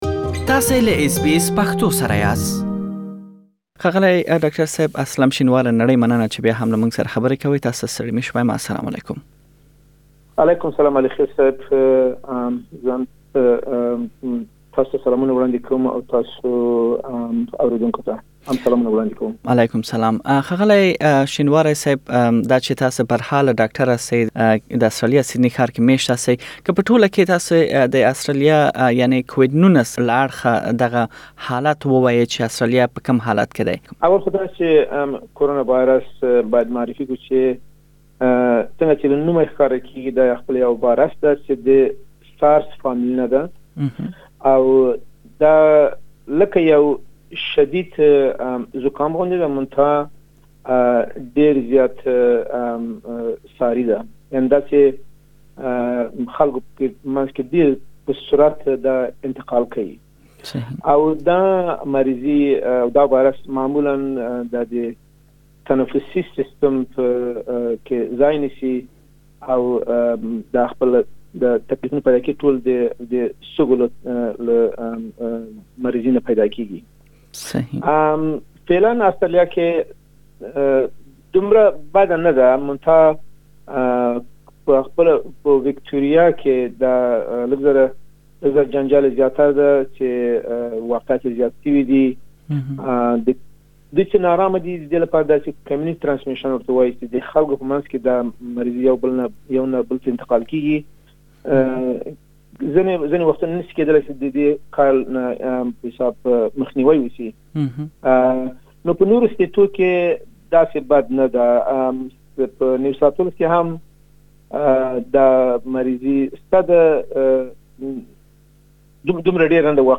بشپړه مرکه دلته اوريدلی شئ.